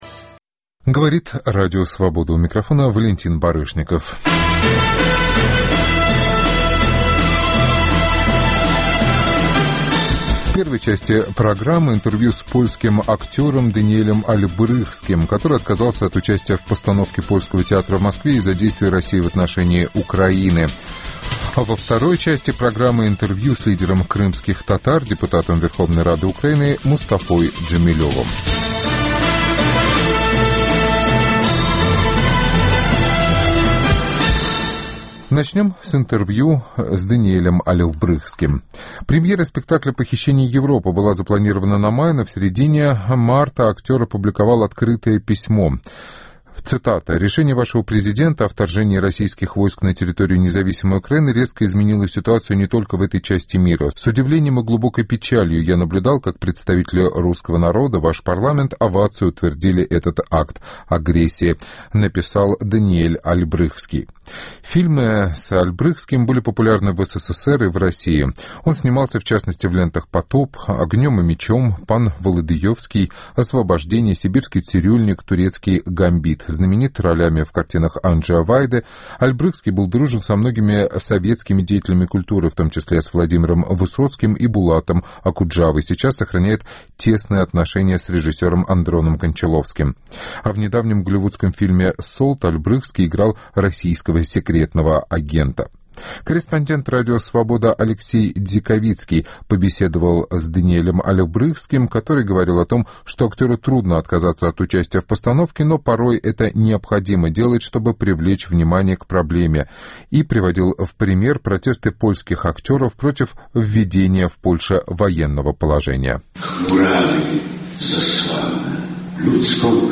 Интервью с польским актером Даниэлем Ольбрыхским, отказавшимся участвовать в московской театральной постановке из-за действий России в отношении Крыма. Интервью с депутатом Верховной Рады Украины, бывшим председателем Меджлиса крымско-татарского народа Мустафой Джамилевым о ситуации в Крыму.